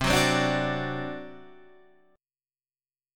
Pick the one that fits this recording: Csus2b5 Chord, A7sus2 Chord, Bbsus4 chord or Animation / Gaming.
Csus2b5 Chord